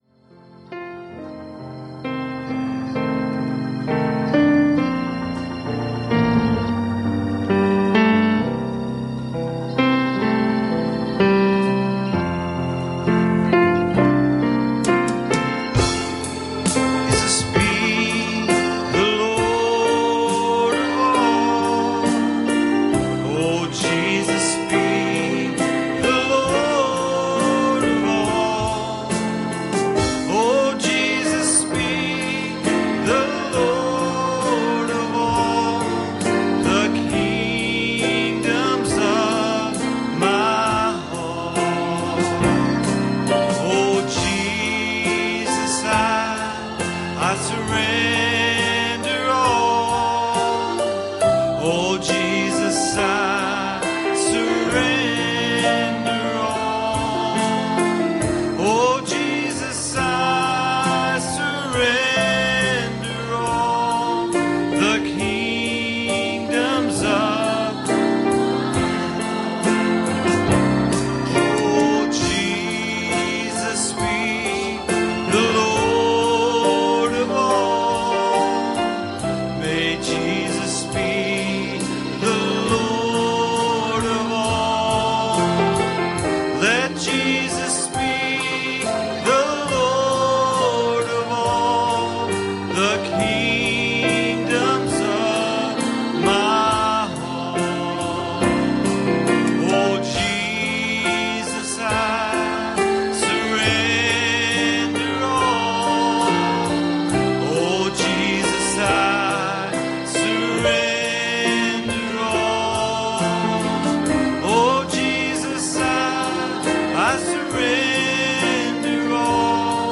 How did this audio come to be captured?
Series: Sunday Evening Services 1 John 4:20 Service Type: Sunday Evening « Speak The Word Pt36